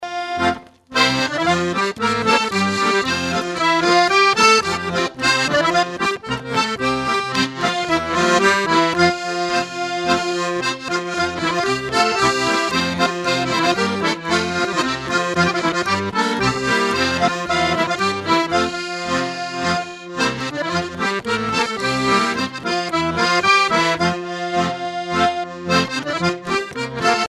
Chants brefs - A danser
Coueff's et Chapias Groupe folklorique
en spectacle
Pièce musicale inédite